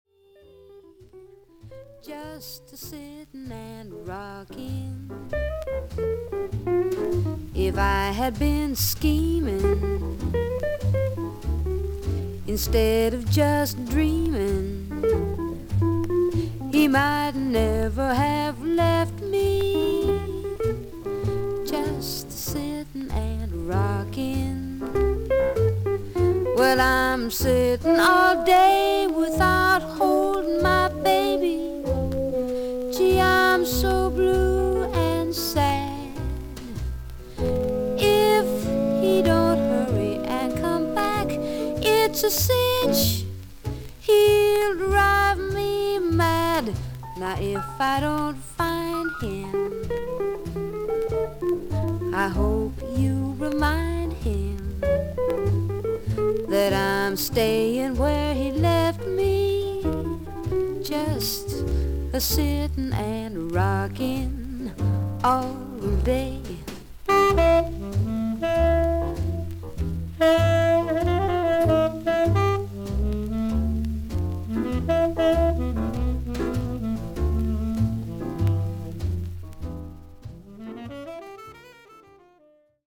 少々ノイズあり。
ほかはVG+〜VG++:少々軽いパチノイズの箇所あり。クリアな音です。
女性ジャズ・シンガー。クールで愛らしい希少なクリア・ヴォイス。